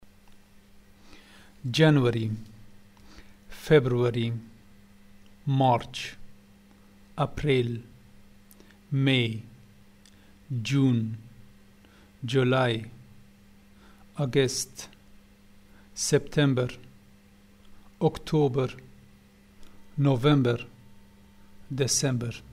(Listen to the month names as pronounced in two cities in Afghanistan.)
Dialect of Kabul